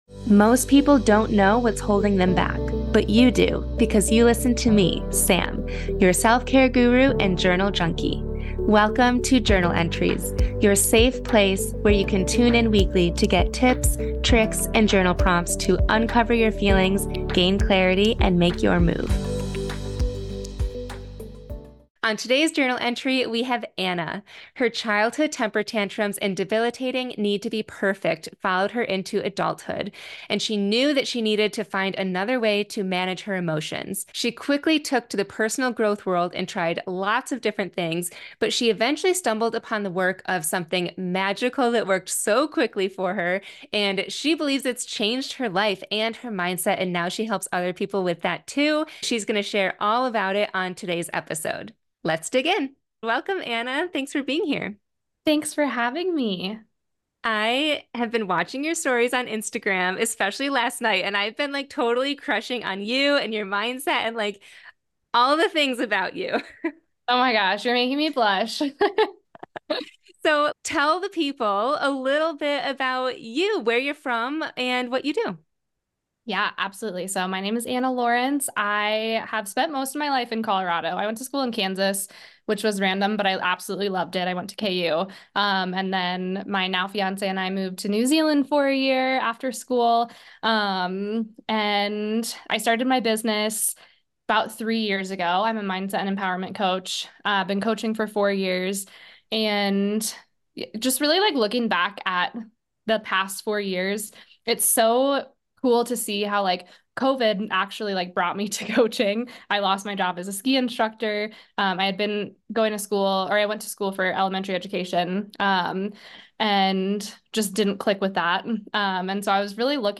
We chat about how to STOP self sabotaging and people pleasing in your life. It's time to start feeling your best and having fun again!